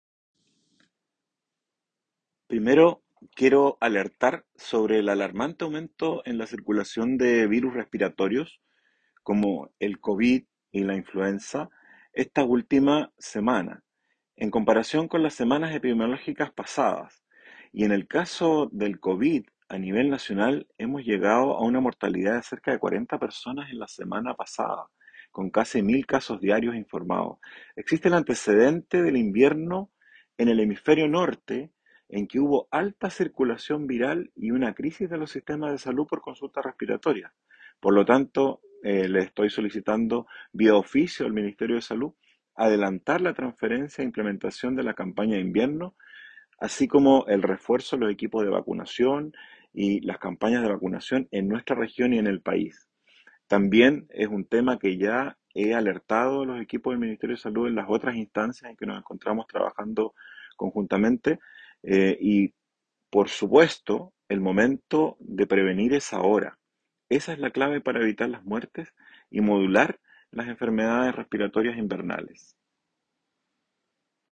Cuña-Dip-Rosas-Minsal.m4a